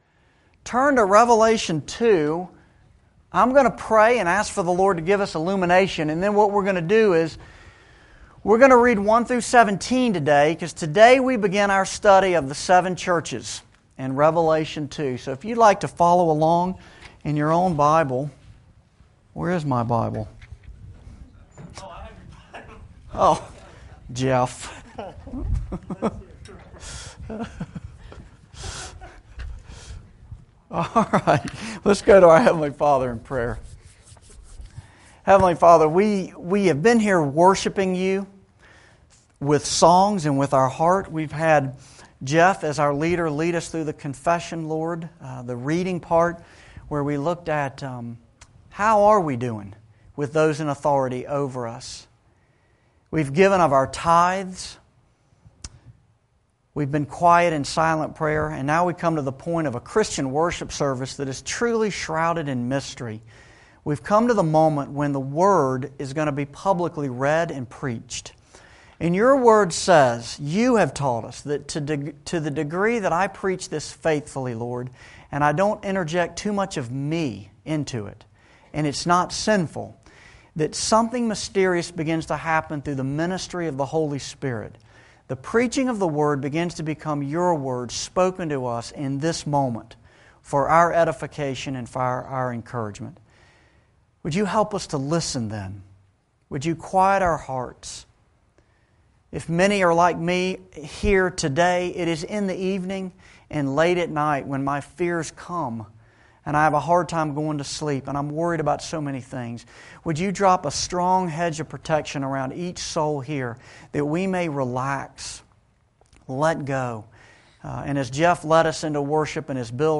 Revelation-Sermon-5.mp3